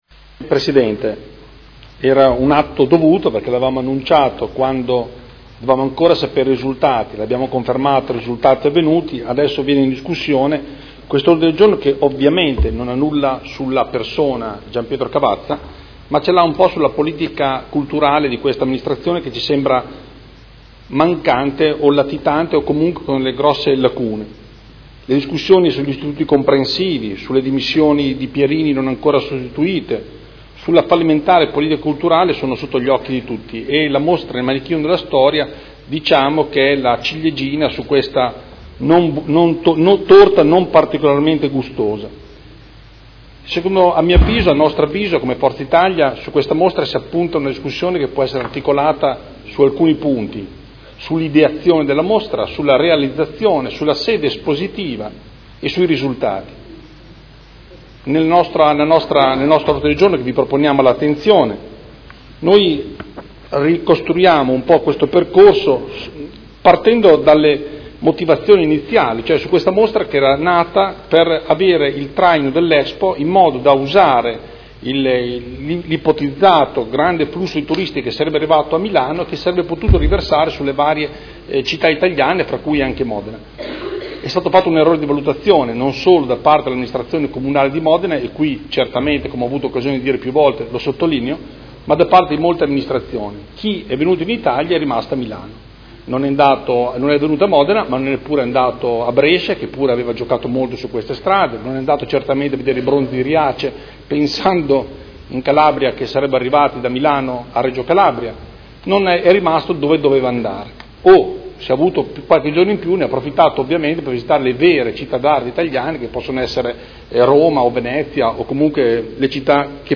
Andrea Galli — Sito Audio Consiglio Comunale